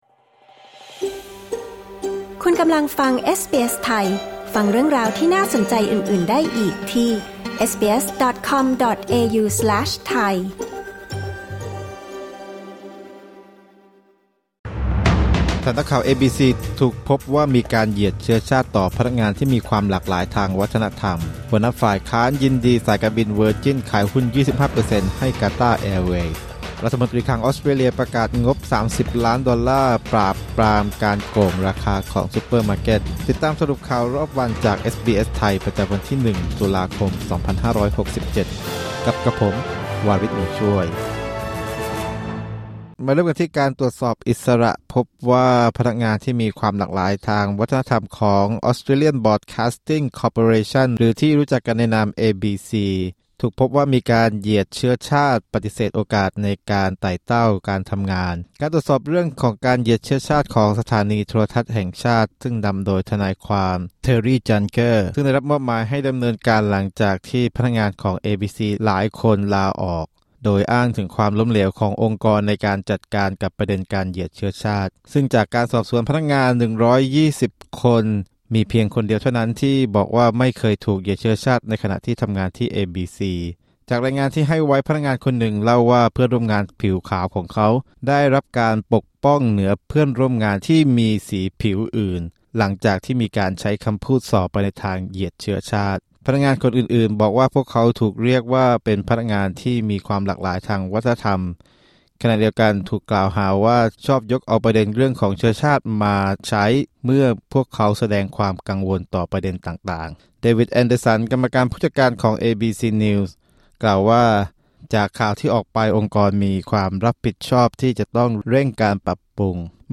สรุปข่าวรอบวัน 01 ตุลาคม 2567